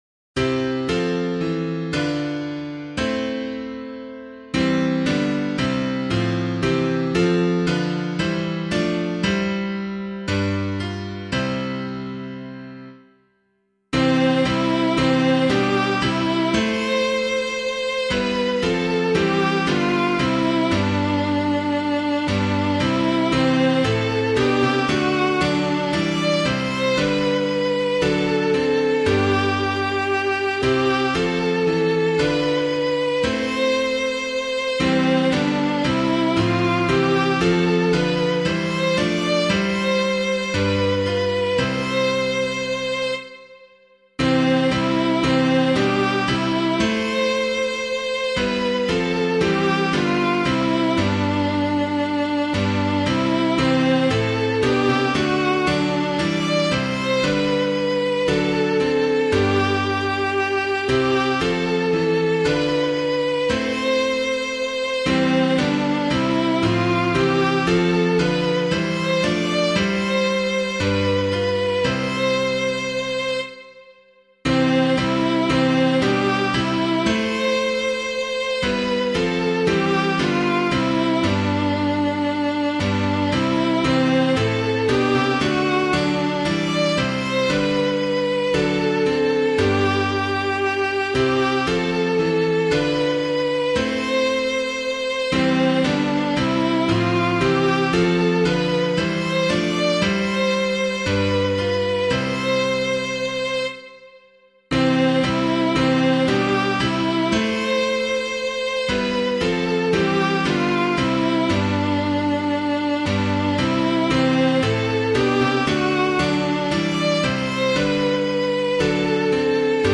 Public domain hymn suitable for Catholic liturgy.
Rejoice the Lord Is King [Wesley - DARWALL'S 148TH] - piano.mp3